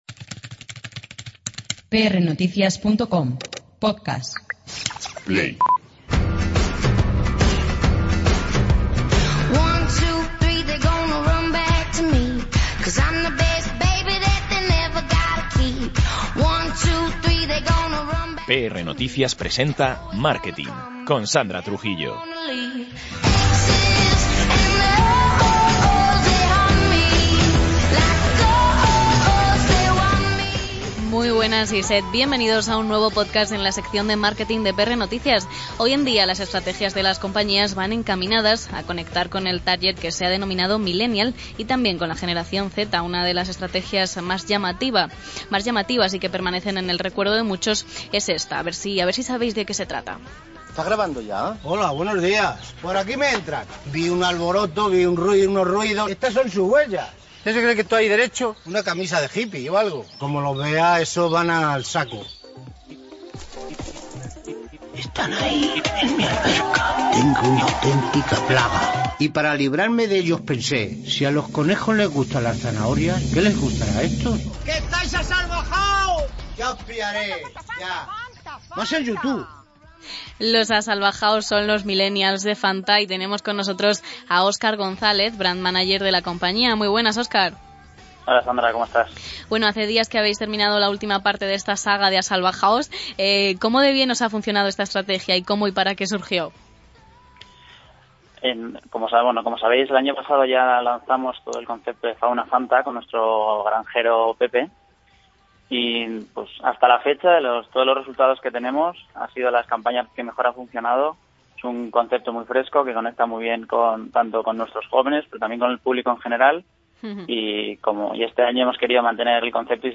prnoticias entrevista